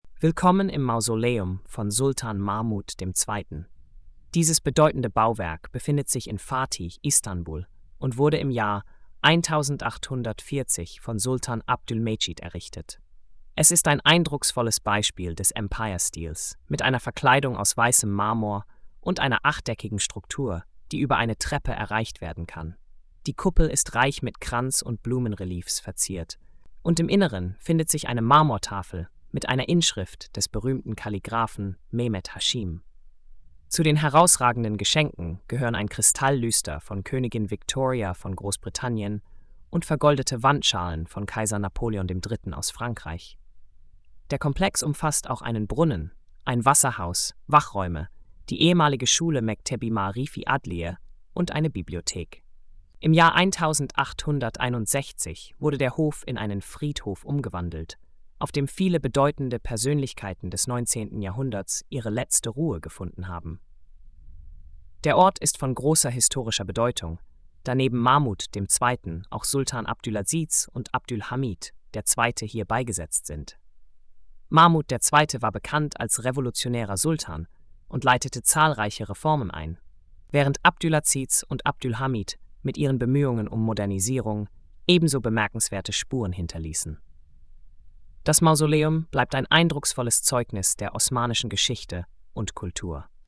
Audio Erzählung: